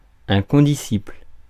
Ääntäminen
Synonyymit promotionnaire Ääntäminen France: IPA: /kɔ̃.di.sipl/ Haettu sana löytyi näillä lähdekielillä: ranska Käännöksiä ei löytynyt valitulle kohdekielelle.